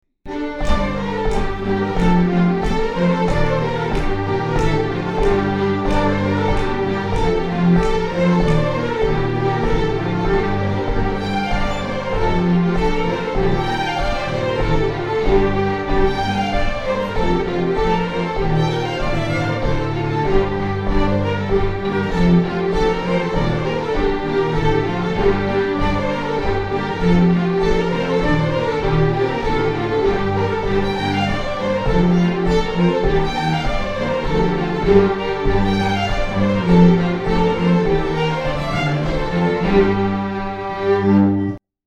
Traditional Scottish Fiddle Music